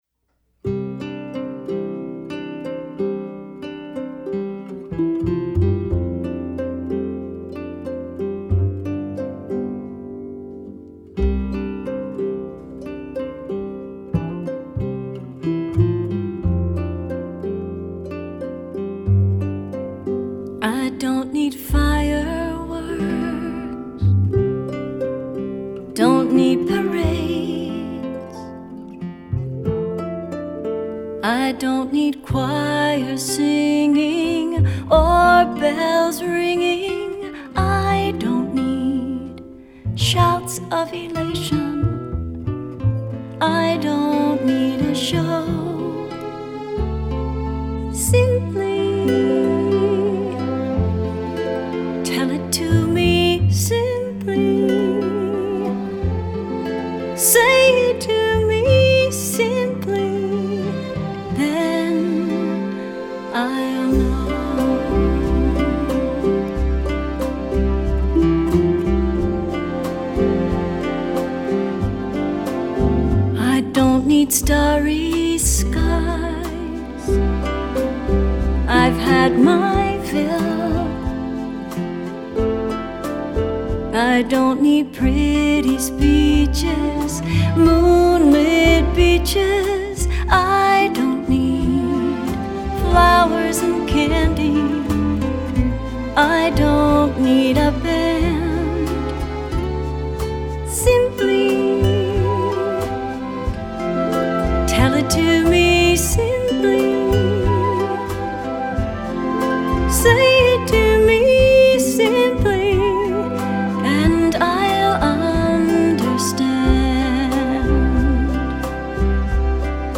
would be played by harp and guitar.